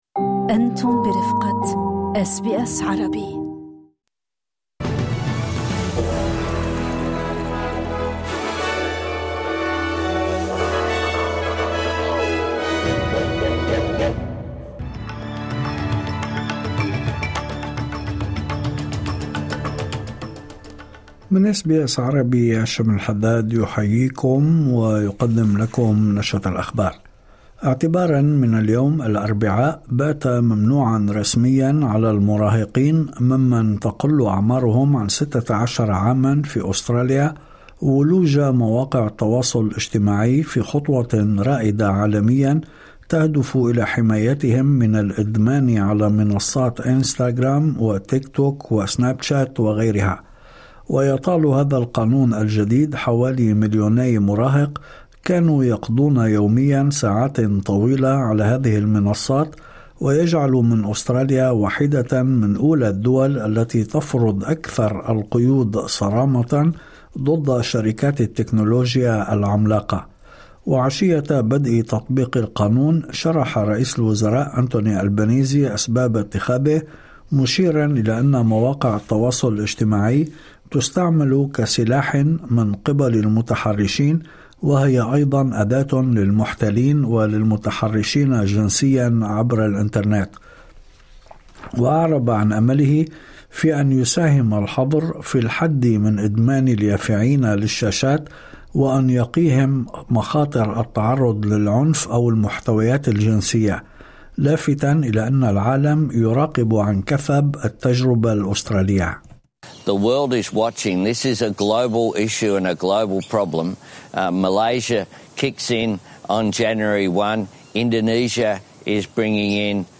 نشرة أخبار الظهيرة 10/12/2025